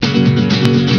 Guitar
guitar.wav